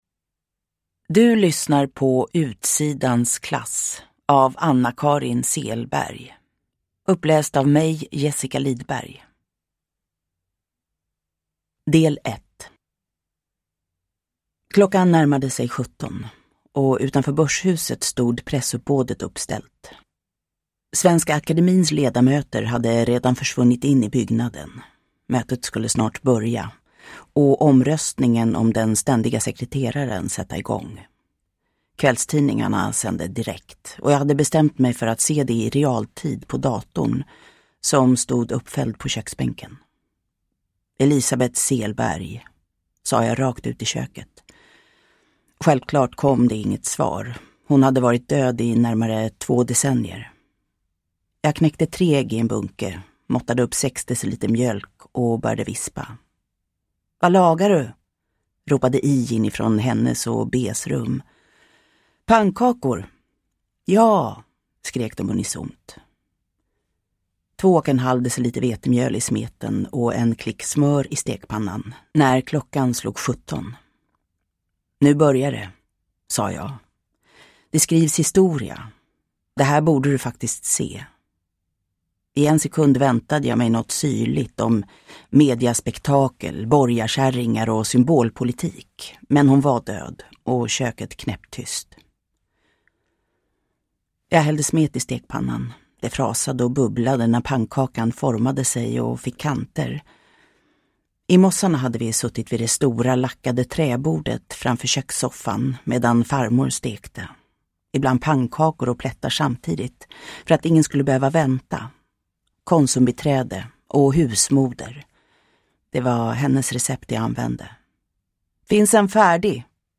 Utsidans klass – Ljudbok
Uppläsare: Jessica Liedberg